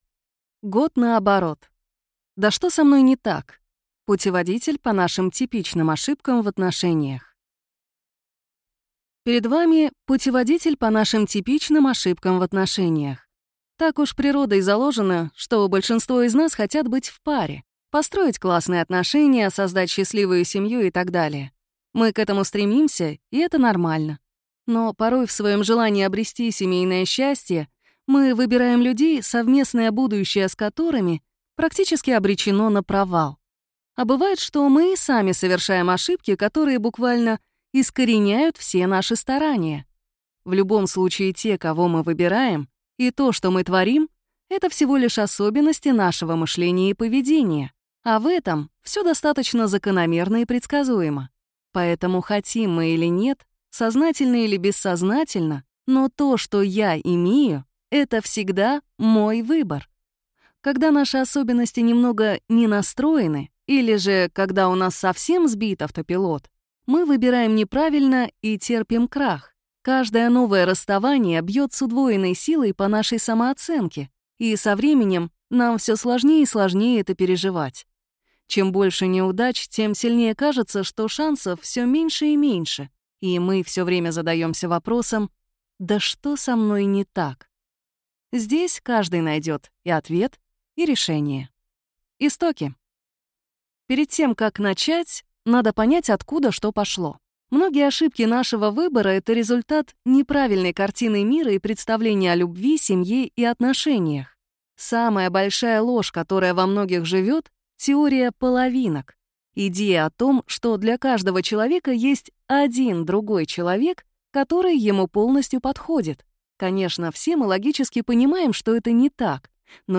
Аудиокнига Да что со мной не так?! Путеводитель по нашим типичным ошибкам в отношениях | Библиотека аудиокниг